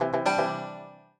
banjo_cecegd1ce.ogg